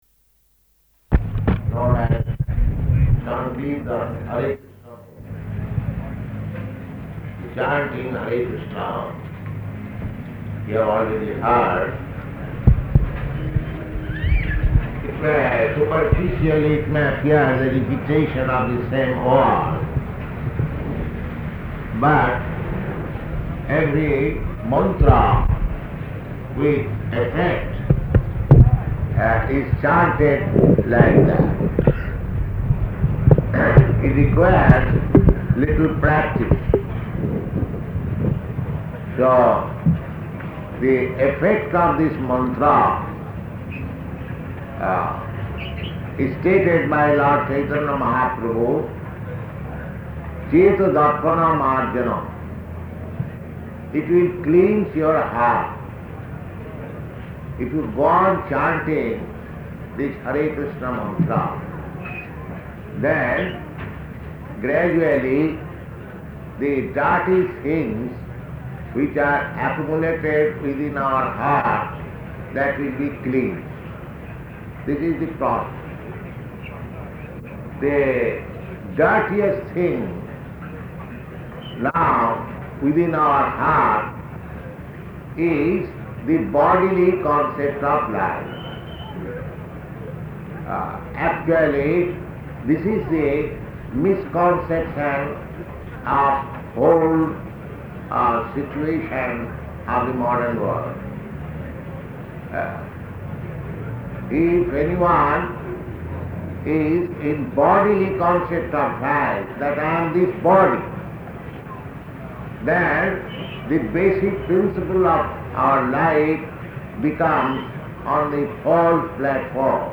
Lecture at Auckland University